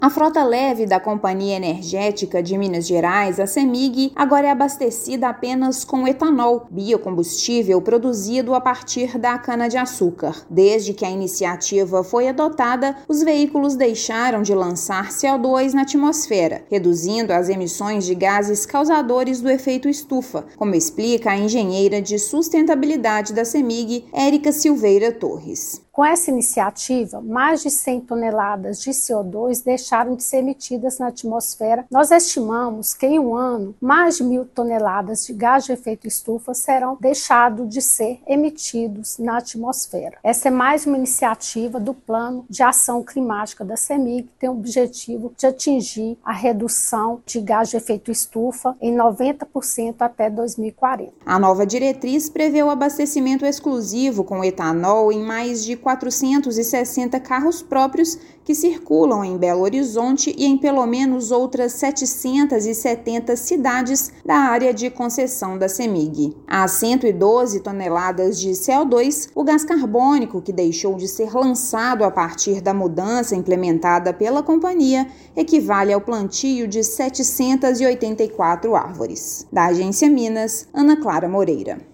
Veículos da companhia passam a ser abastecidos apenas com o biocombustível de origem vegetal que libera menor quantidade de gases poluentes. Ouça matéria de rádio.